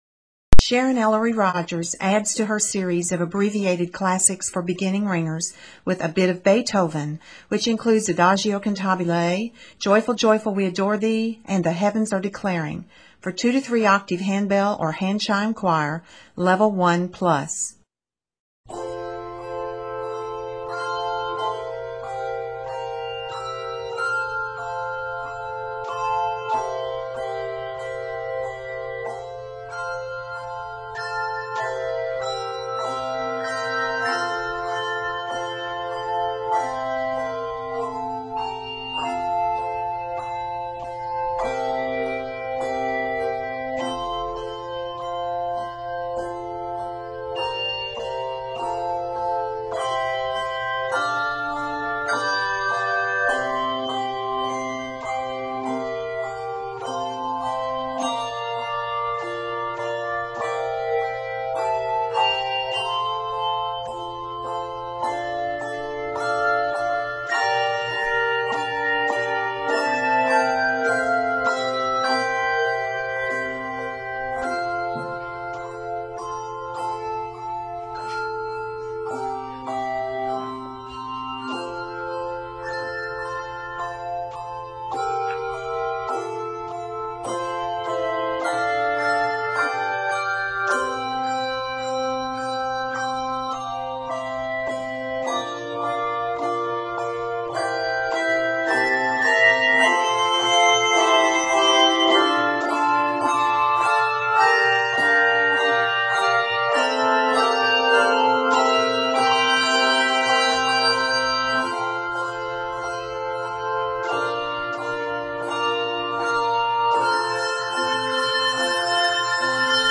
for 2-3 octave handbell or handchime choir